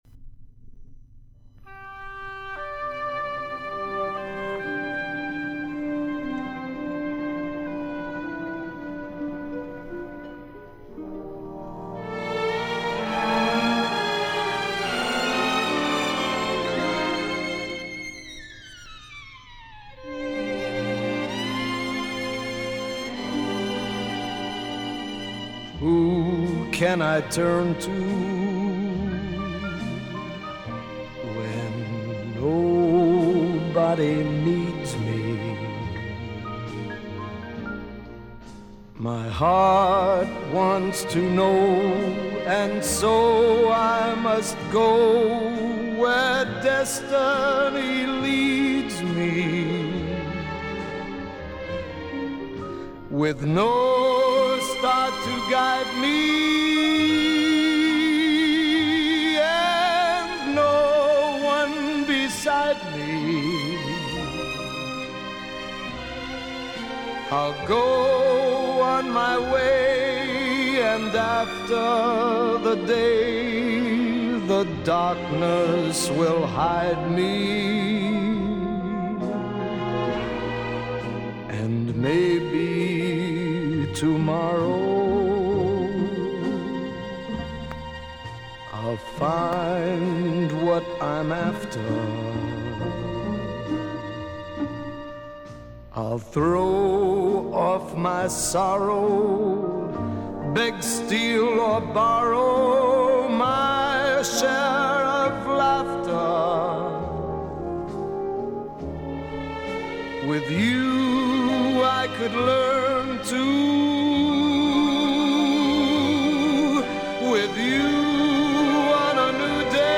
Жанры Свинг
Поп-музыка
Джаз